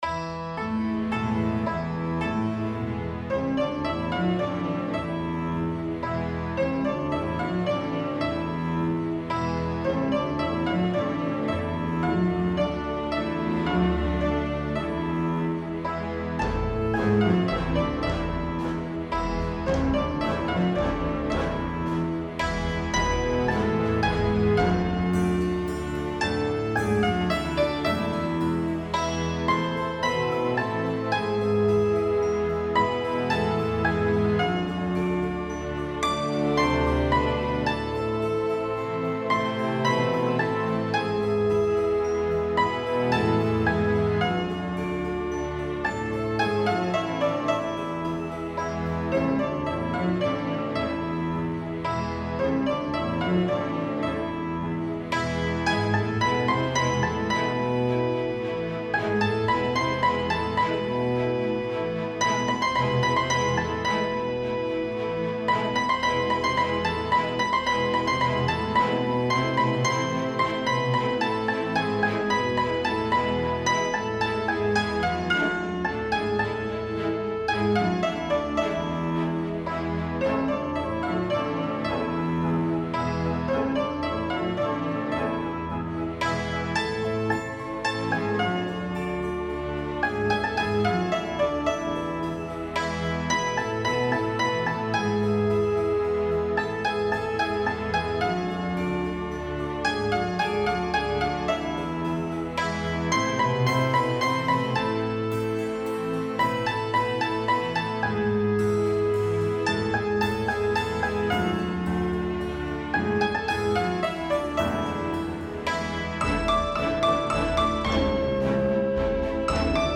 در دو مثال زیر از آکوردهایی با فواصل غربی و شرقی استفاده شده است.
• این دوملودی که با فواصل ریزپرده هارمونیزه شده بود ؛ فضای گنگ و مبهمی داشت و دلپذیر نبود فکر کنم به خاطر همین است که می گویند باید فواصل ریزپرده ای را برای هراونیزه تعدیل کرد ؛ البته اینکه اینها را از موسیقی سنتی حذف کنیم کاملا اشتباه است زیرا آنجا بافت تکصدایی است و لزومی ندارد ولی برای چندصدایی آهنگساز ناچار است.